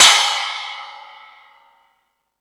Space Drums(33).wav